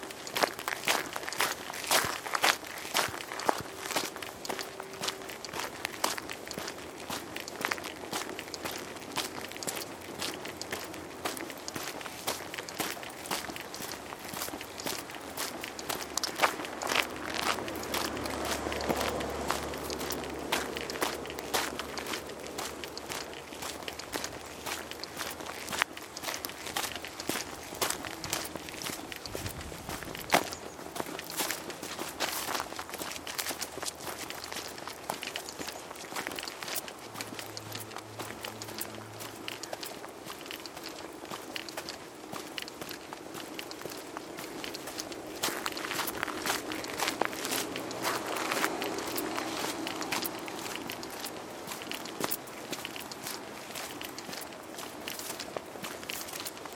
walking on gravel